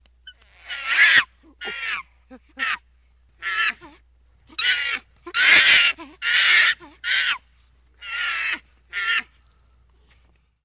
Monkey sound - Eğitim Materyalleri - Slaytyerim Slaytlar